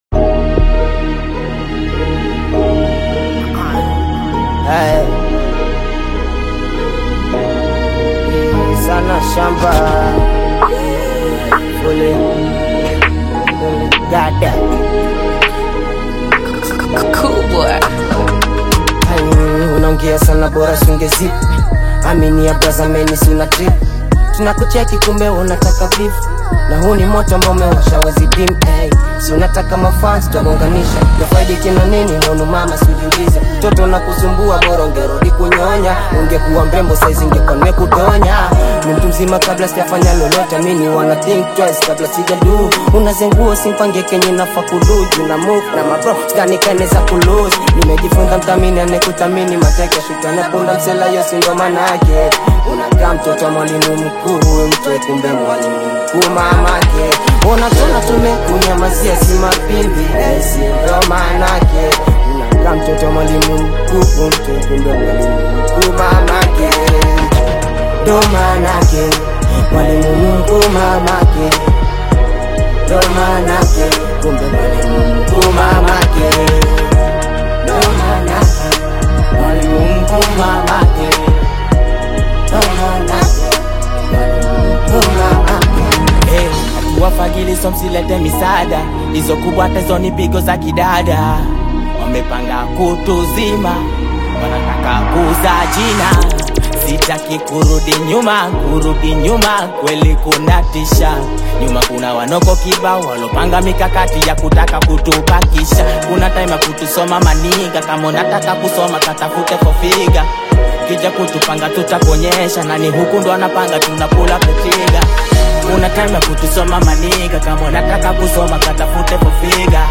vibrant Tanzanian Hip Hop single
lively delivery and authentic urban sound